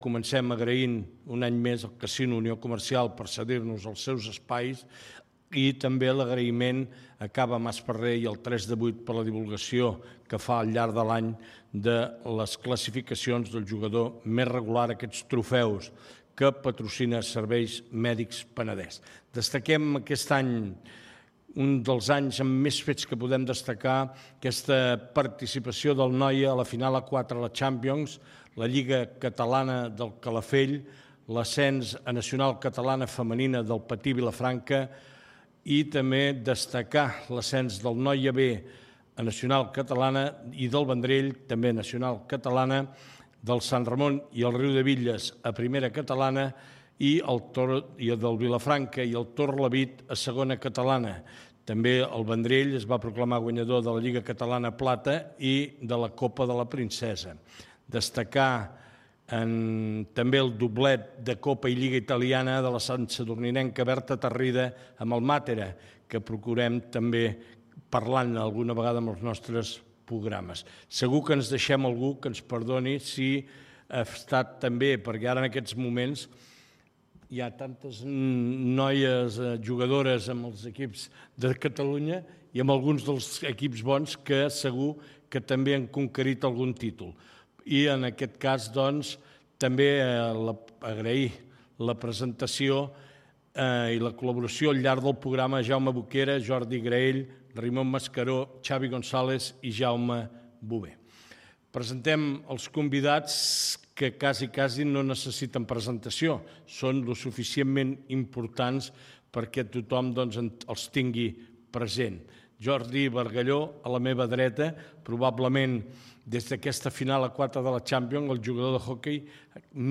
Especial final de temporada des del Casino